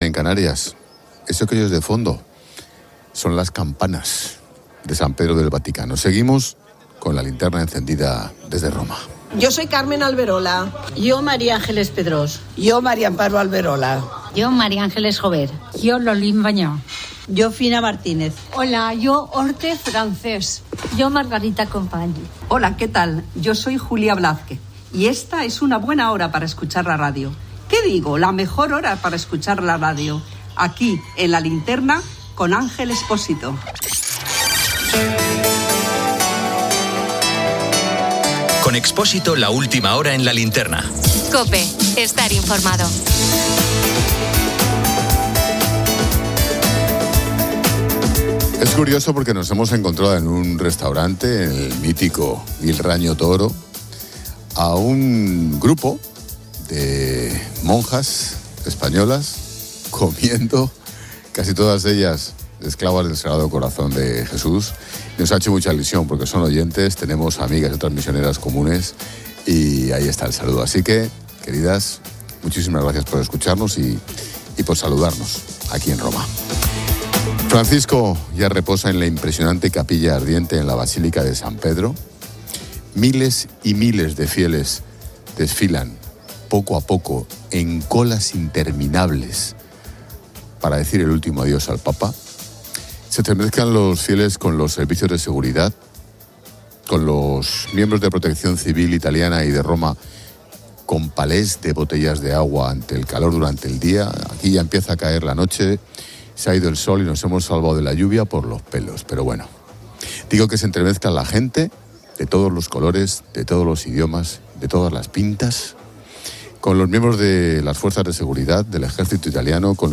Eso que oyes de fondo son las campanas de San Pedro del Vaticano. Seguimos con la linterna encendida desde Roma.